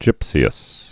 (jĭpsē-əs)